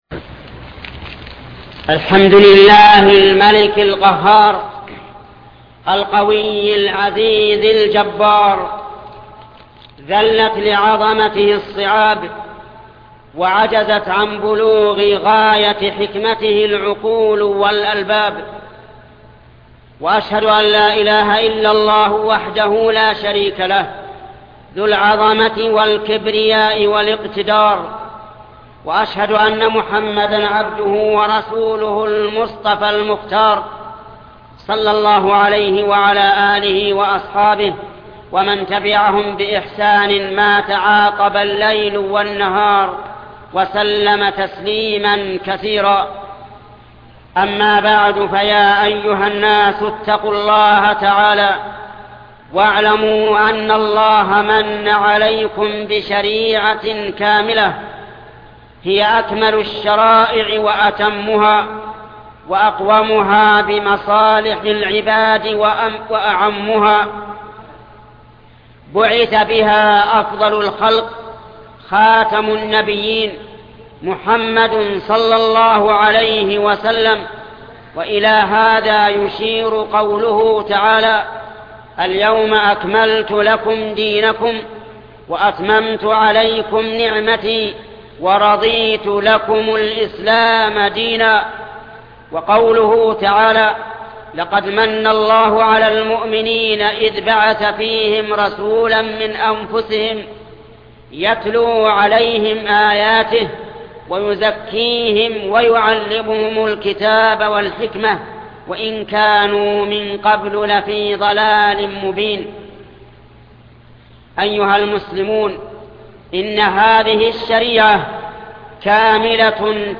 خطبة واجبنا تجاه ولاة الأمور الشيخ محمد بن صالح العثيمين